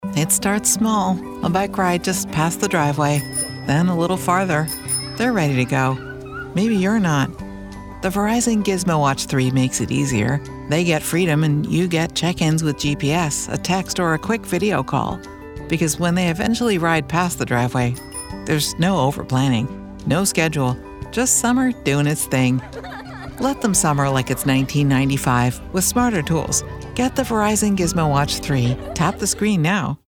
Verizon Spot
My voice has been described as a “warm hug".